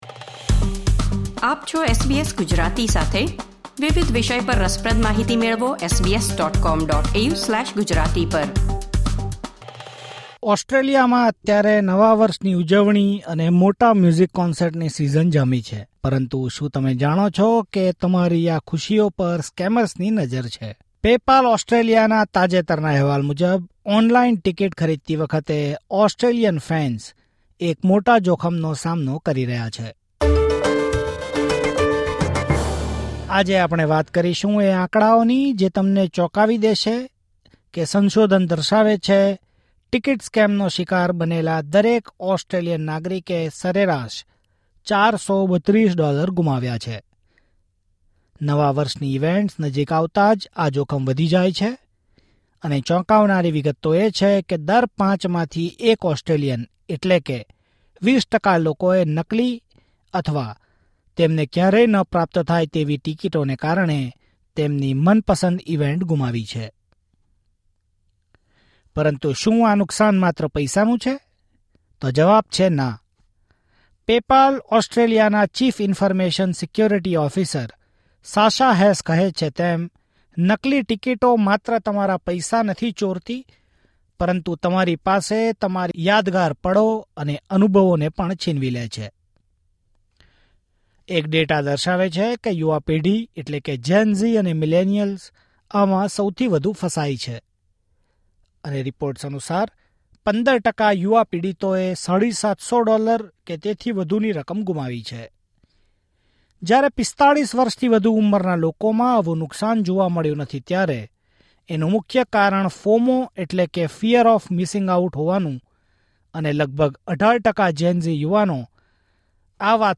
Taking advantage of the fear of getting cheap tickets and missing out on an event, scammers advertise deceptively cheap tickets and extort money. Read the full details in this report.